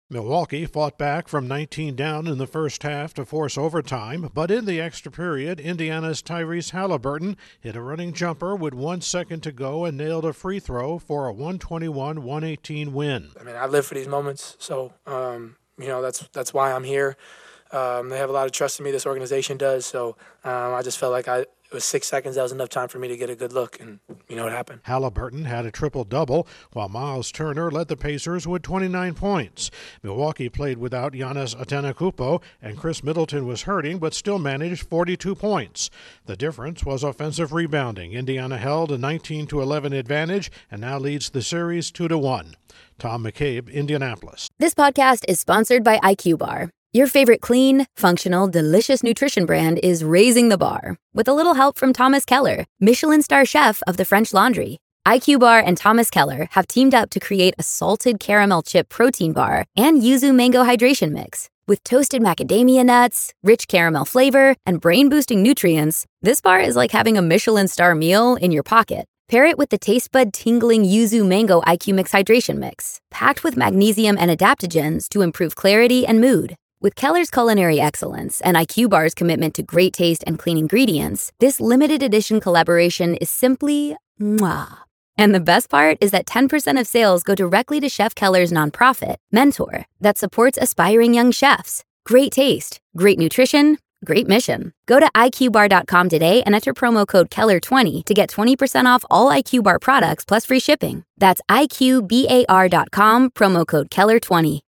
The Pacers knock off the Bucks after wasting a big lead. Correspondent